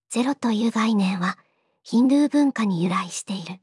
voicevox-voice-corpus / ita-corpus /四国めたん_ヒソヒソ /EMOTION100_012.wav